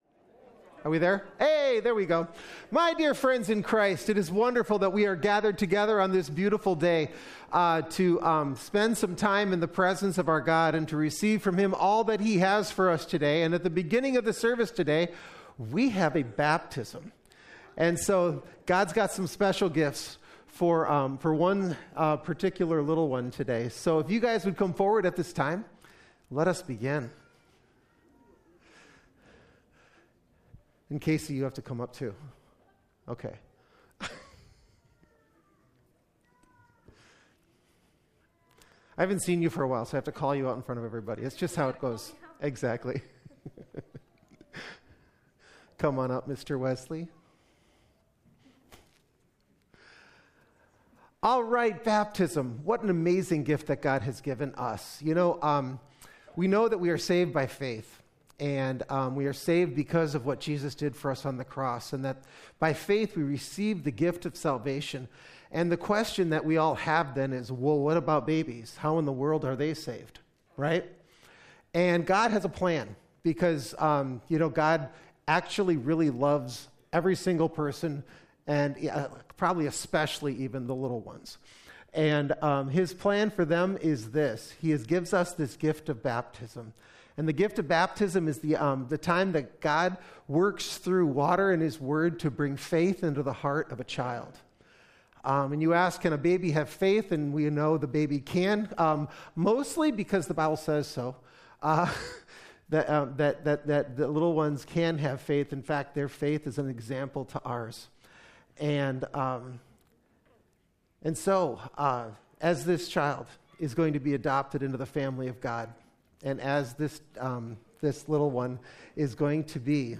2025-November-9-Complete-Service.mp3